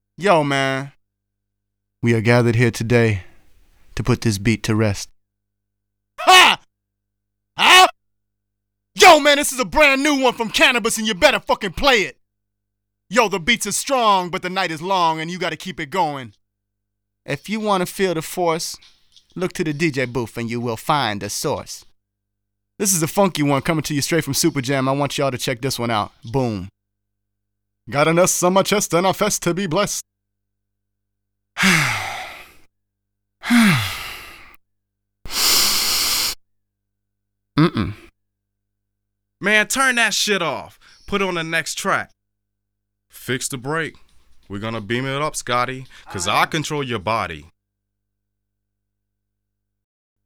32 Male AdLib 2.wav